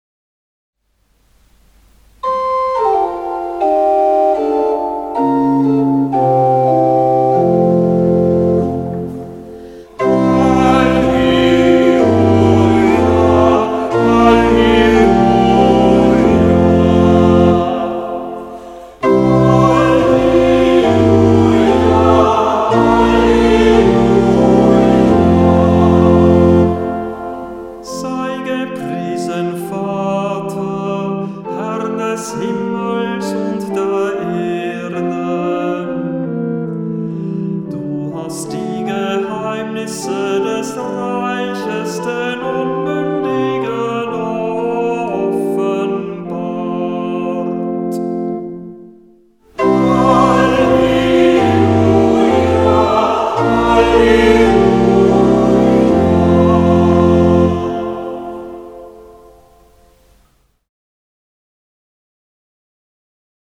Halleluja aus dem Gotteslob